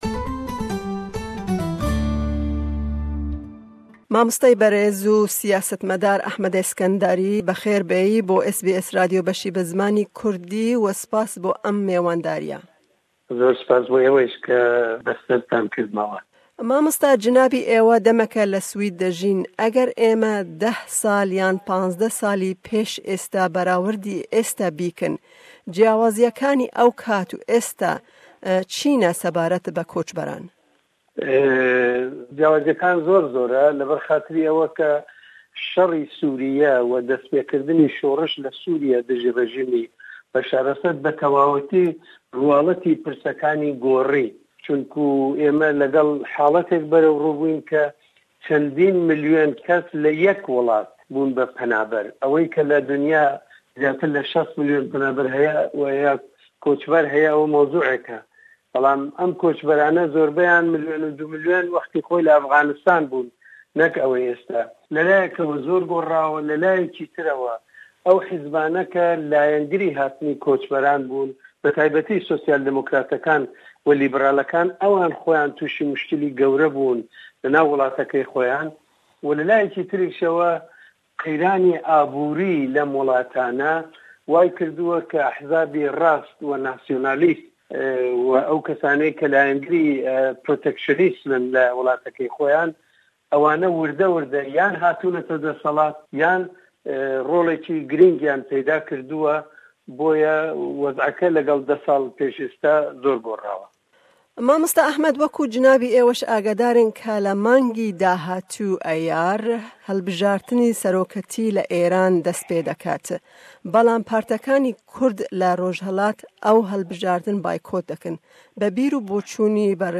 Me hevpeyvînek derbarê helbijartinên Îranê yên di meha li pêsh me Gulanê, rewsha Kurdan li Rojhilat, û em herweha derbarê pêvajoya êrîshên firrokî yên Turkiyê li ser Shingalê û Qereçoxê li Sûriyeyê axifîn.